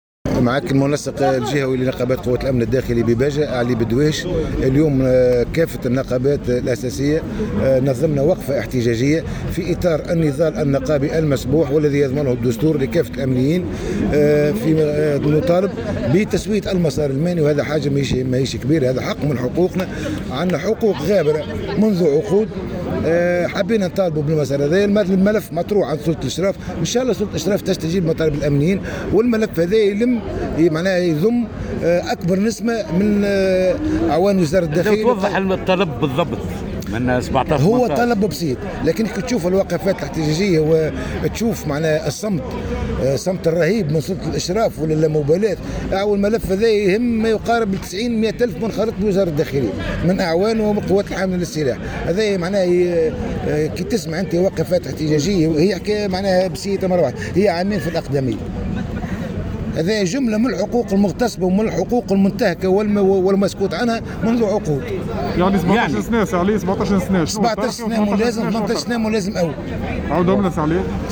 PODCAST النقابات الأمنية بباجة تحتجّ امام اقليم الحرس الوطني